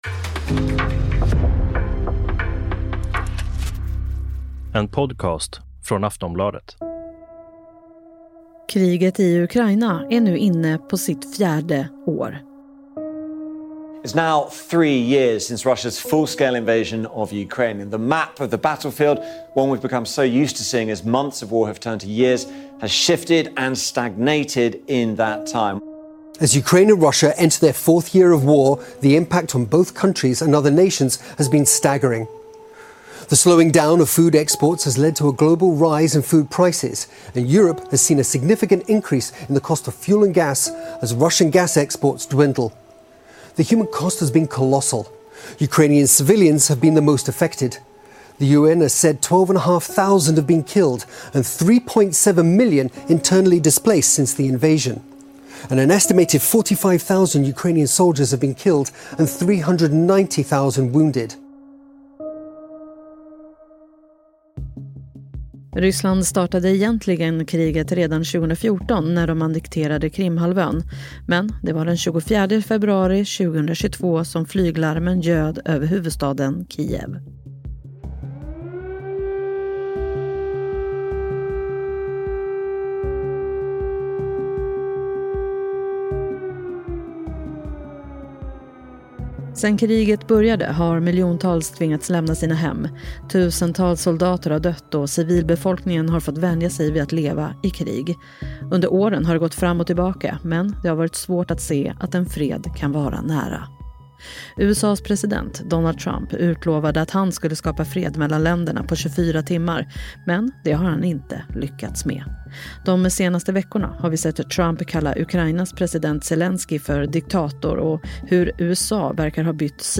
Klipp från: BBC News, Sky News, Al Jazeera.